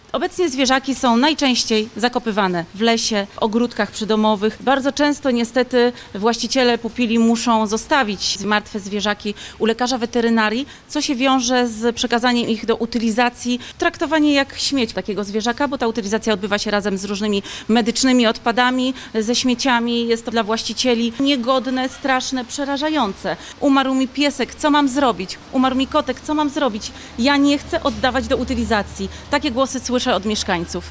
– Mówi radna Bogumiła Tiece.